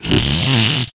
yoshi_snore1.ogg